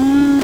camera_in.wav